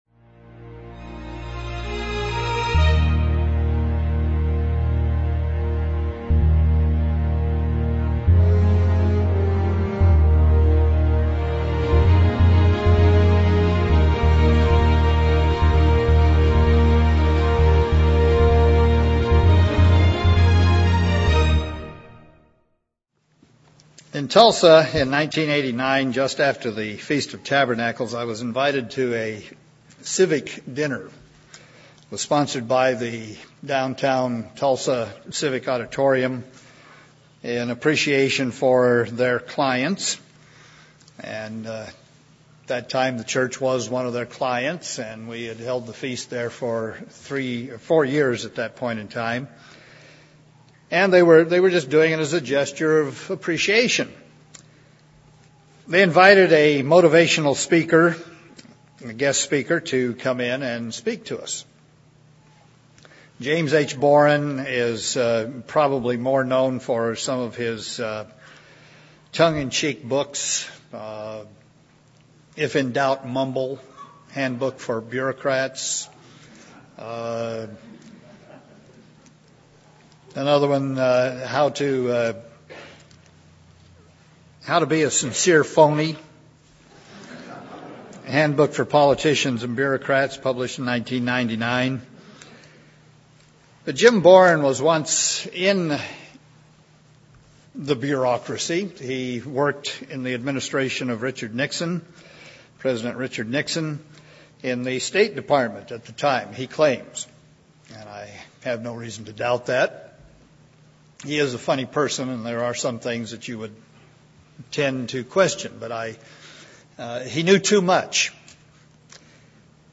This sermon was given at the Anchorage, Alaska 2010 Feast site.